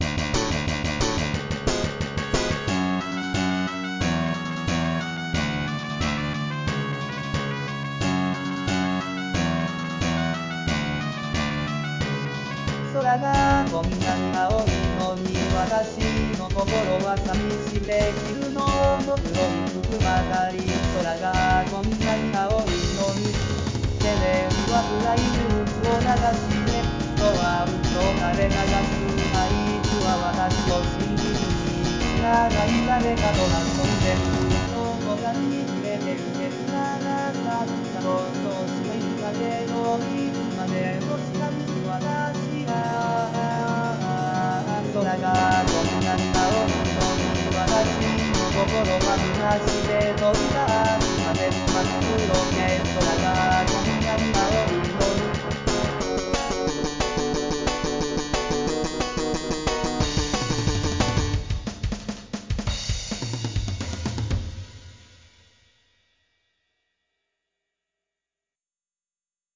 日本語歌詞から作曲し、伴奏つき合成音声で歌います。